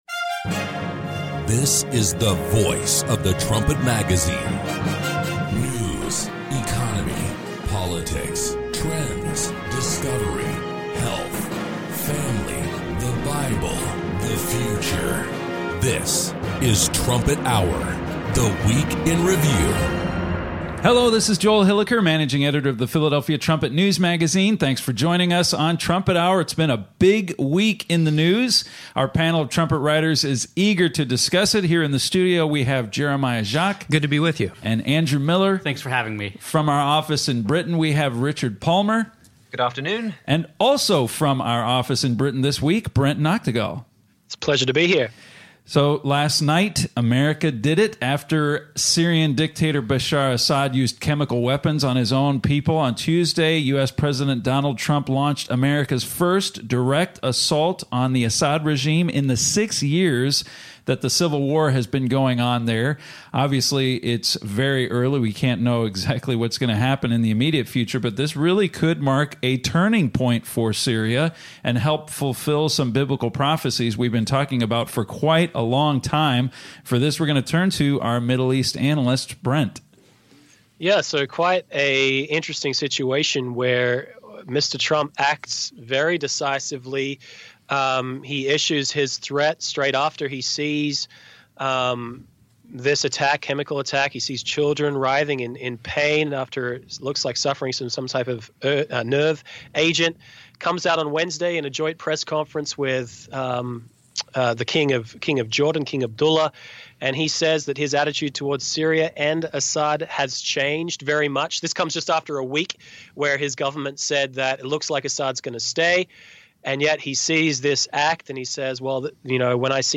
Trumpet Hour is the voice of the Philadelphia Trumpet newsmagazine. Join the discussion as Trumpet staff members compare recent news to Bible prophecy.